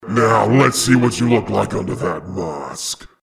This is an audio clip from the game Team Fortress 2 .
Saxton Hale audio responses